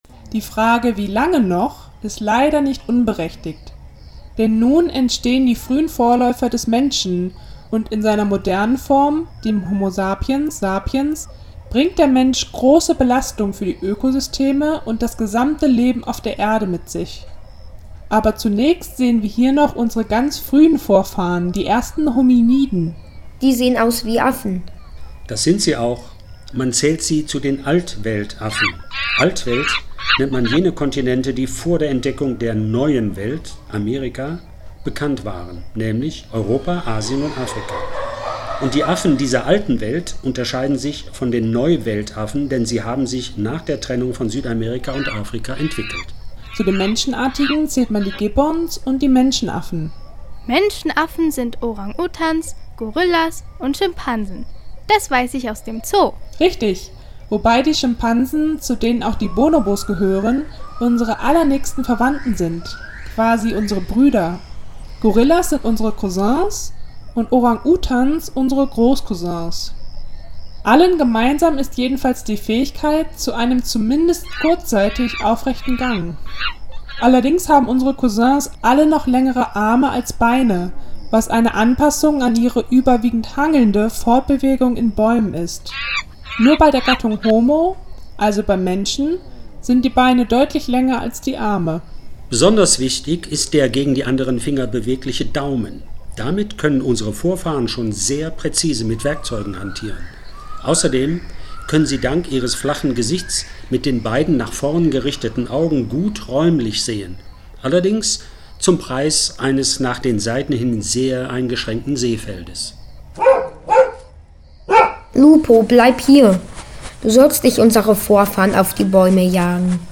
Die letzten 3 Stationen im Hörspiel: